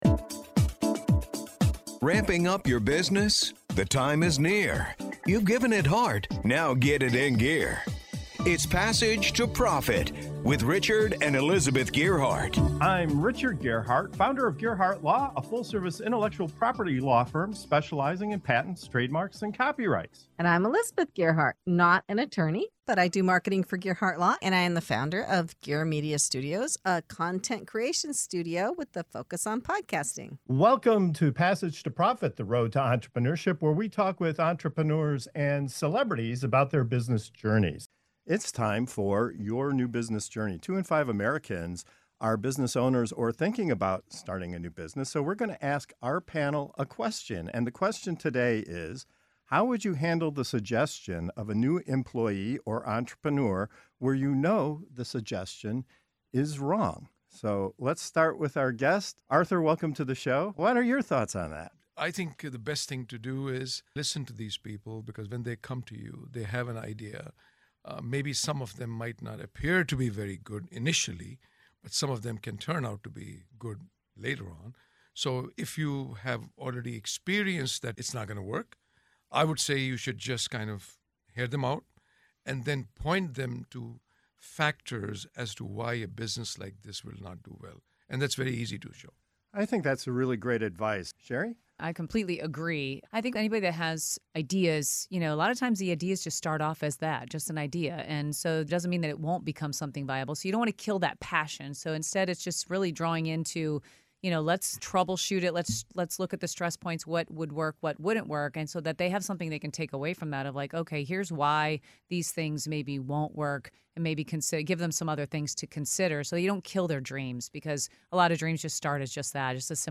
In this segment of "Your New Business Journey" on Passage to Profit Show, our panel tackles a common yet delicate challenge faced by leaders and entrepreneurs: how to respond when a new employee or team member presents an idea you know won’t work.